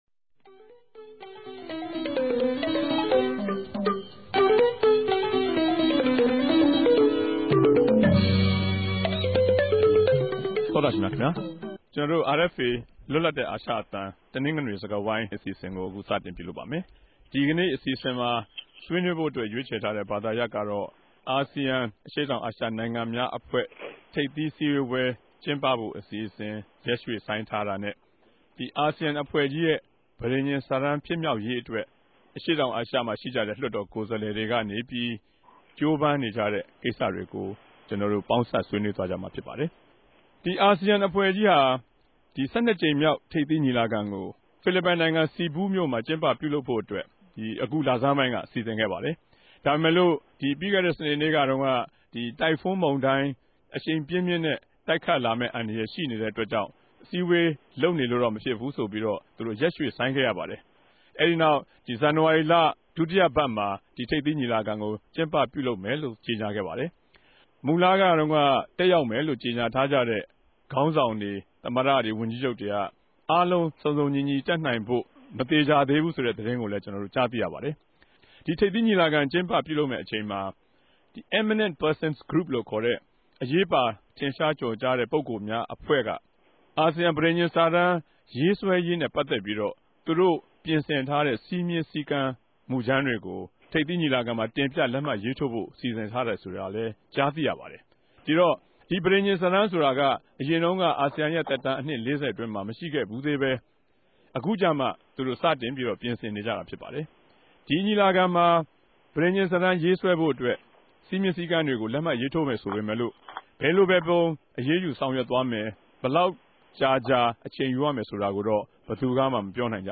႟ုံးခဵြပ်စတူဒီယိုကနေ တယ်လီဖုန်းနဲႛ ဆက်သြယ်္ဘပီး ဆြေးေိံြးတင်ူပထားတာကို နားထောငိံိုင်ပၝတယ်။